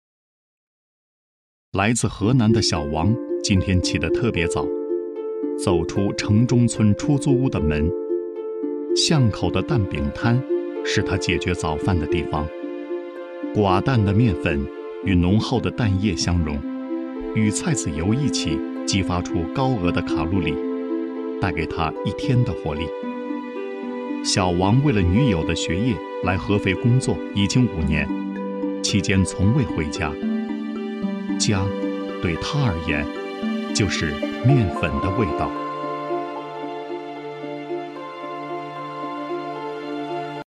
宣传片配音
标签： 浑厚
配音风格： 力度 浑厚 舒缓 大气 时尚 科技 激情